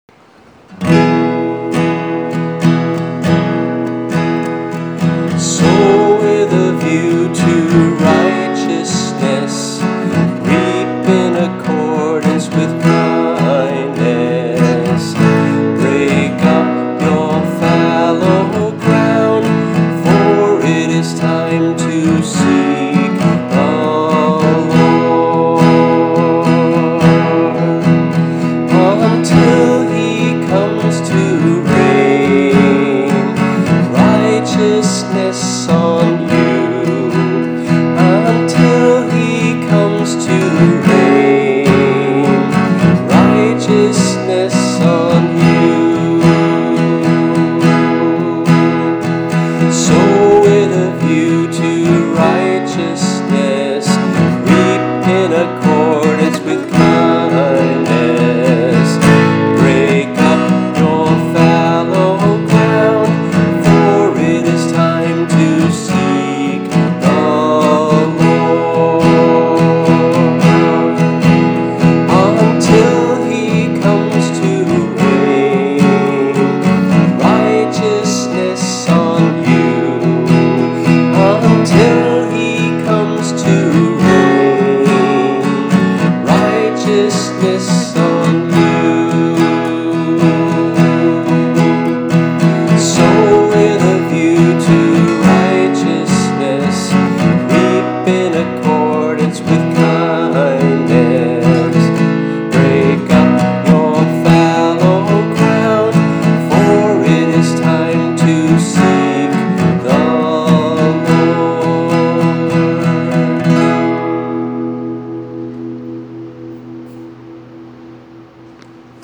[MP3 - voice and guitar]